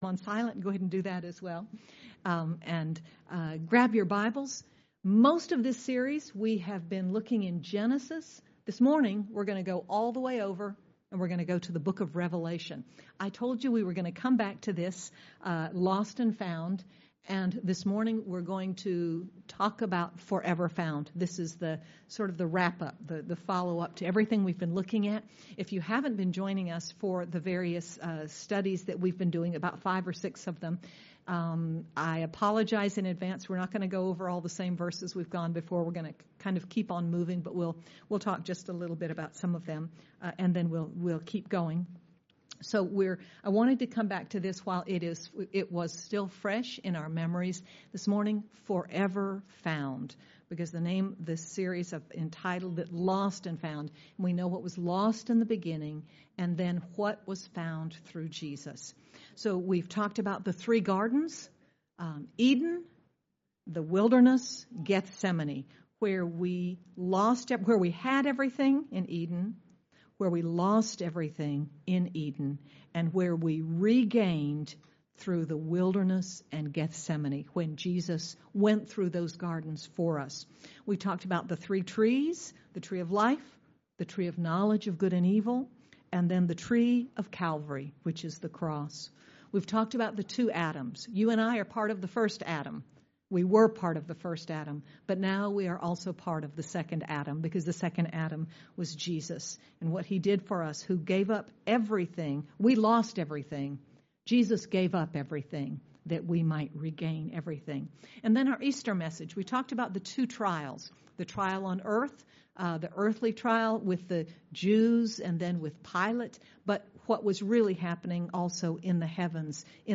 Apr 18, 2021 Forever Found MP3 SUBSCRIBE on iTunes(Podcast) Notes Discussion Sermons in this Series One day, everything that was lost to mankind through sin will be fully restored and more. Be encouraged to persevere and keep going when you see what Jesus has in store for us ahead. Sermon by